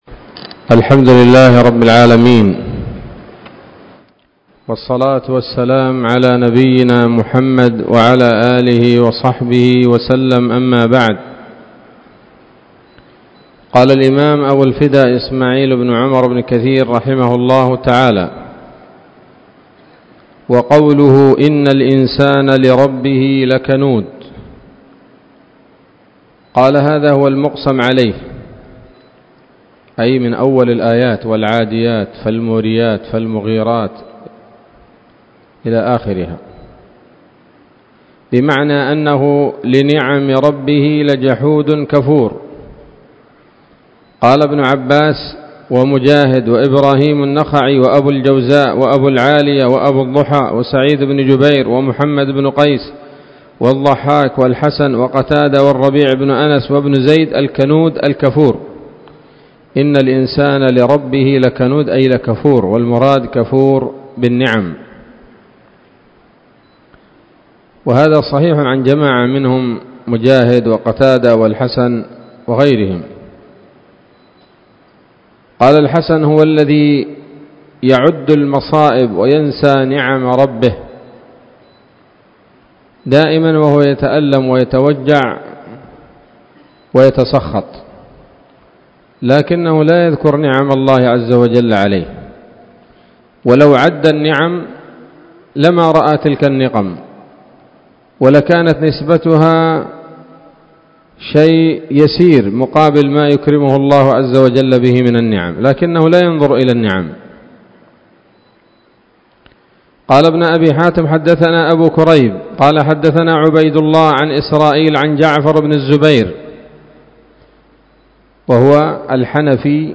الدرس الثاني من سورة العاديات من تفسير ابن كثير رحمه الله تعالى